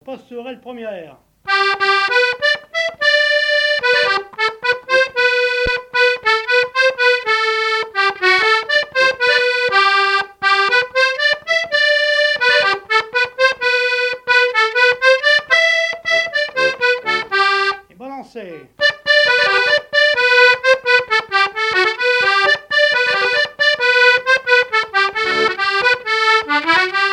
danse : quadrille : pastourelle
Pièce musicale inédite